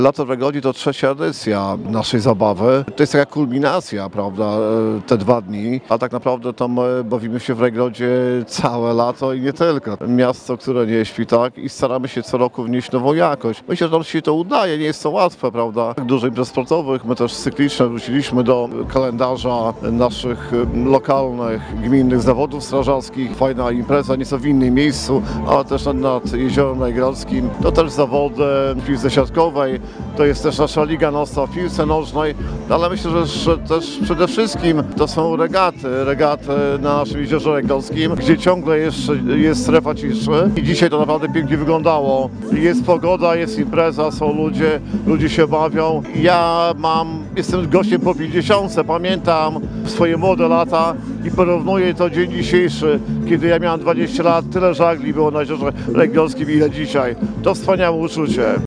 Program tegorocznego „Lata w Rajgrodzie” był bardzo bogaty, mówi Ireneusz Gliniecki burmistrz miasta.